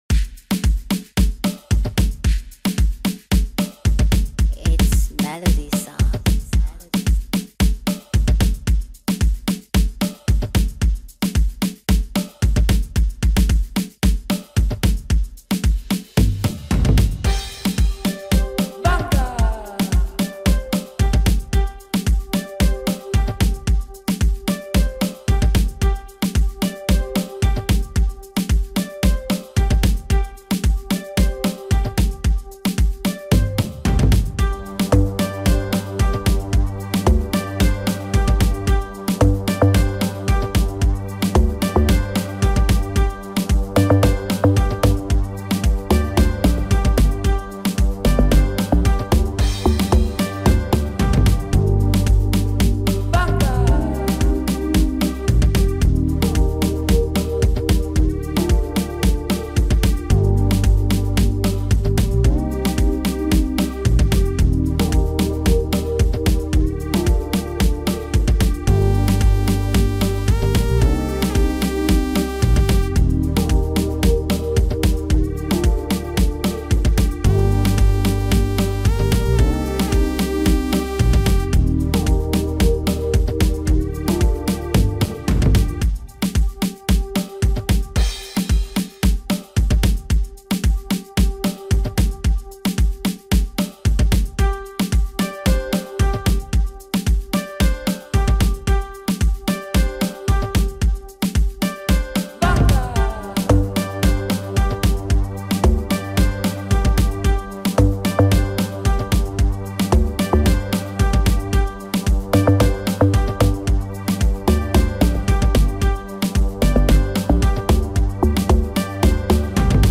instrumental remake